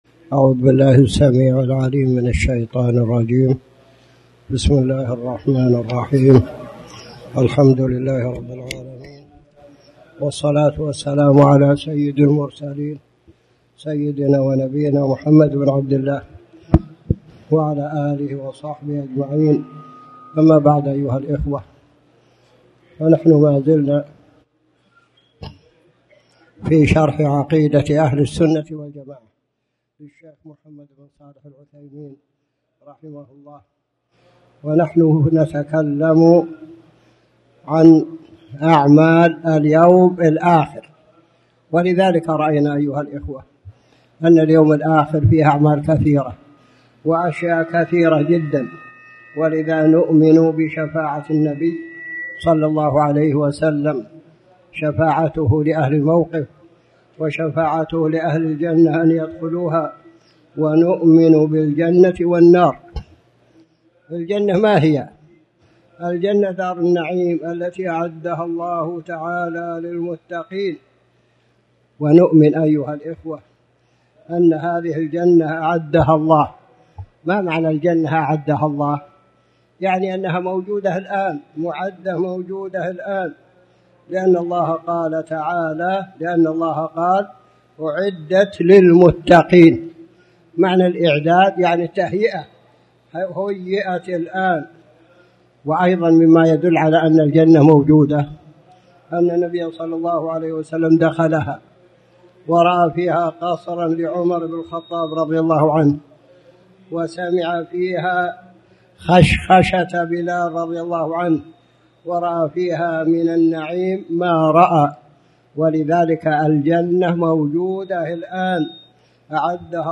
تاريخ النشر ٣ ربيع الأول ١٤٣٩ هـ المكان: المسجد الحرام الشيخ